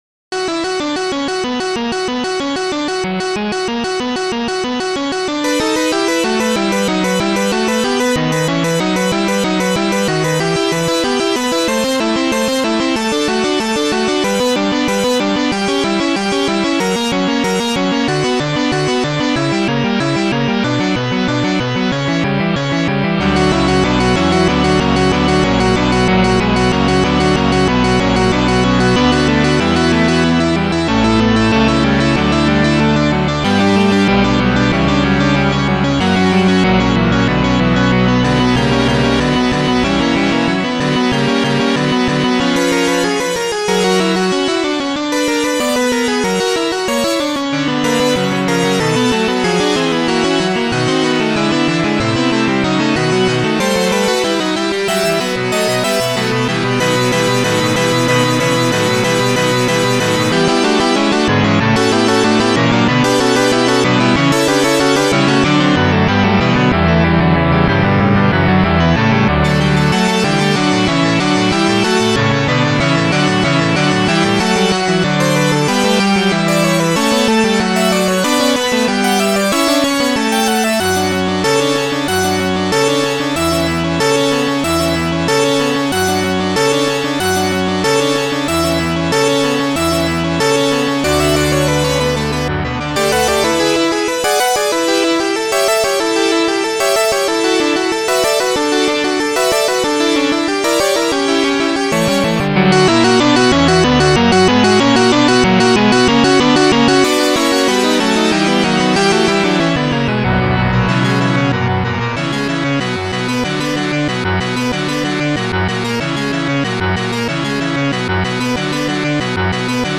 Digital Symphony Module
2 channels
st-09:organ1.1 st-09:Organ1.2